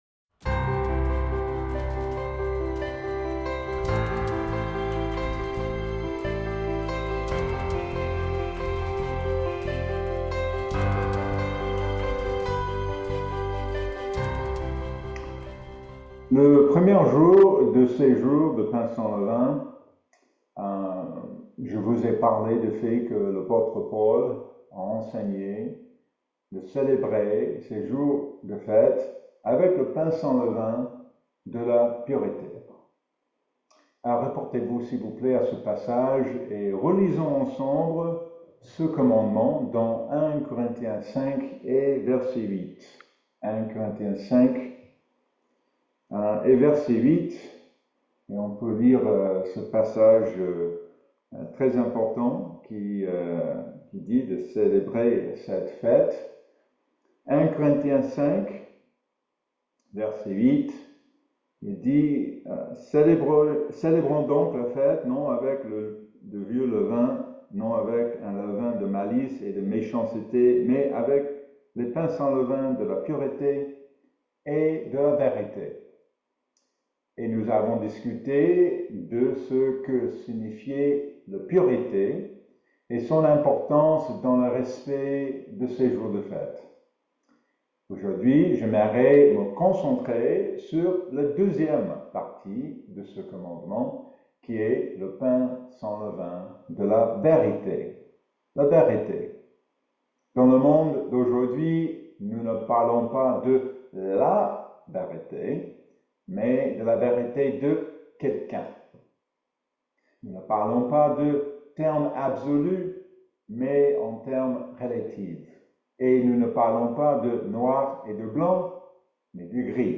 Le sermon aborde l'importance de célébrer les jours saints avec une compréhension de la vérité, en se référant à 1 Corinthiens 5:8. Il souligne comment, dans un monde où la vérité est souvent relative, il est crucial de s'accrocher à la vérité biblique. Le message explore également les défis de discerner la vérité dans un monde saturé d'informations fausses ou manipulées, utilisant l'exemple de Pilate qui questionne ce qu'est la vérité face à Jésus.